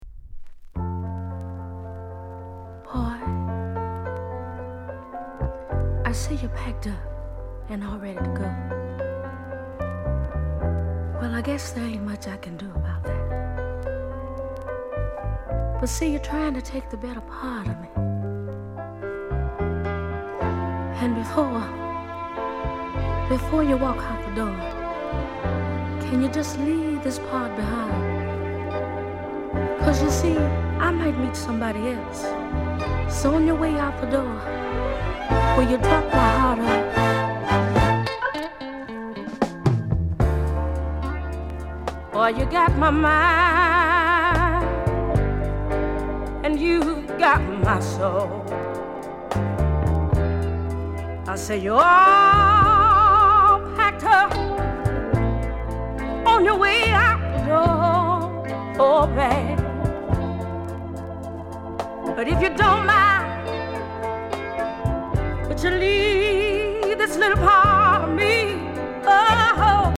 RARE DISCO & SOUL